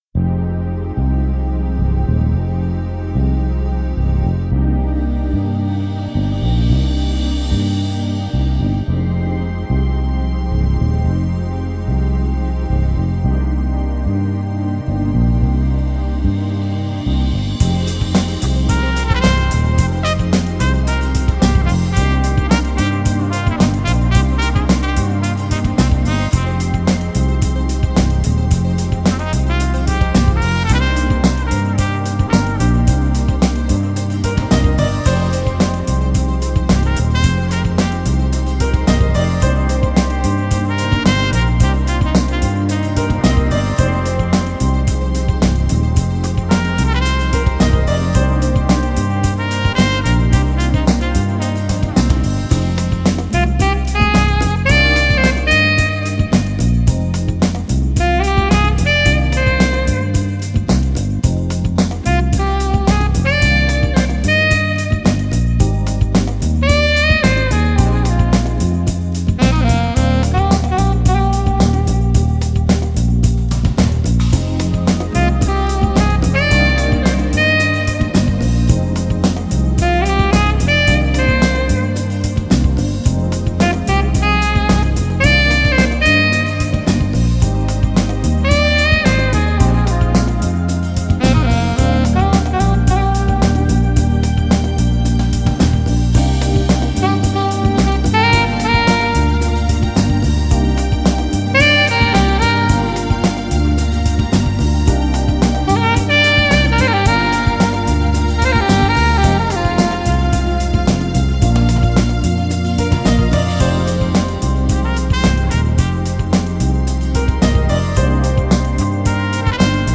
Всем джаза, посоны.